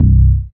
85 DANC BASS.wav